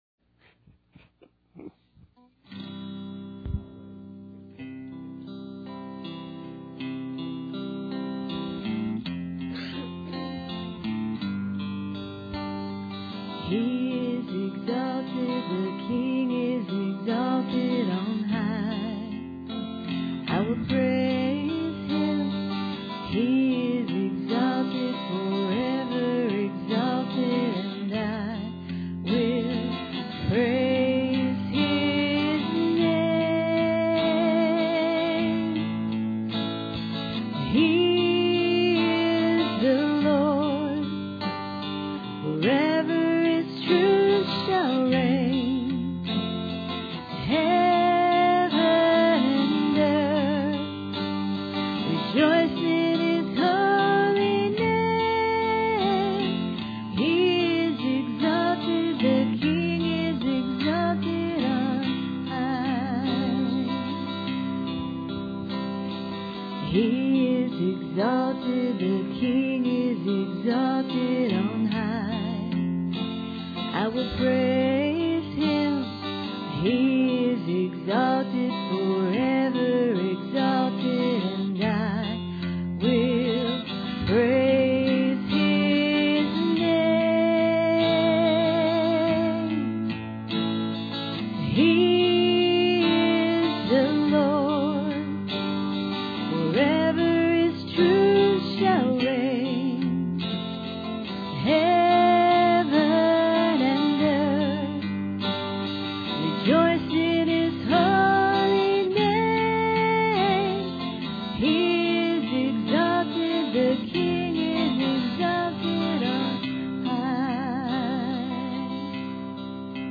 Passage: John 3:16-17 Service Type: Sunday Morning Bible Text